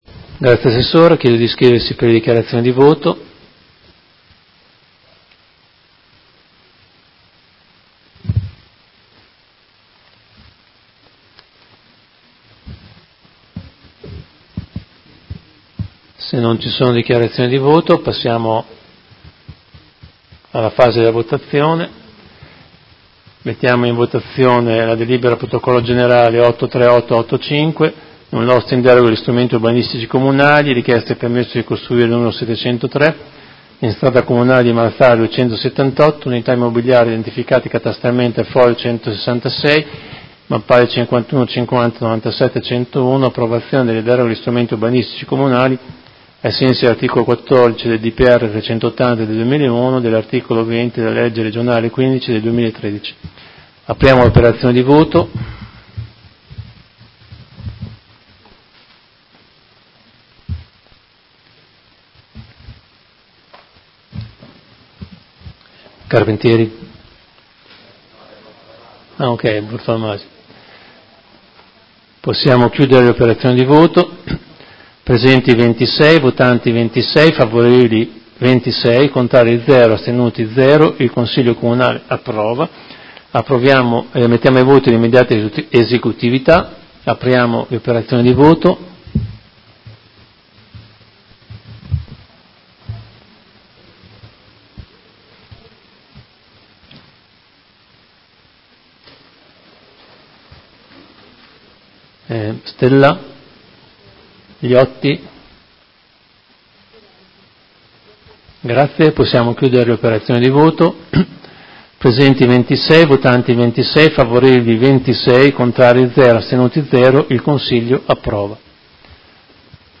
Seduta del 04/04/2019 Come Presidente di turno.